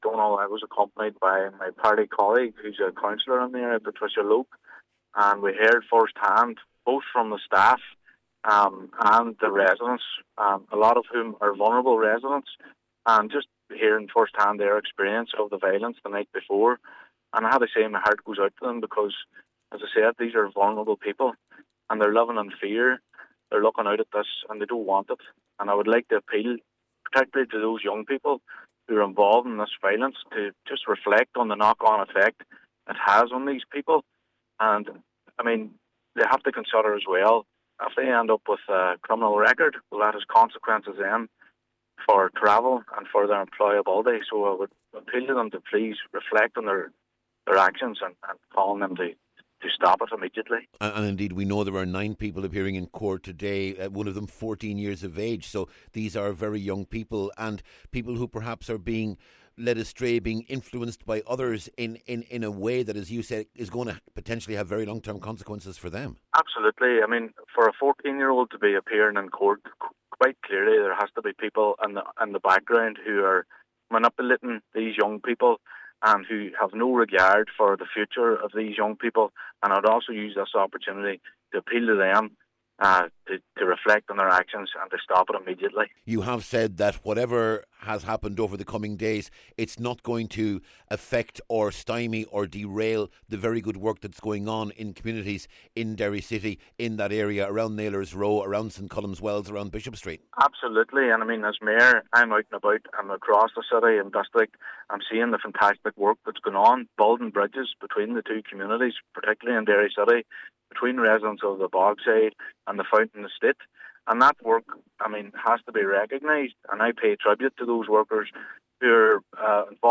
Cllr McHugh told Highland Radio News those behind the incidents must reflect on what they are doing, and the distress they are causing for vulnerable people: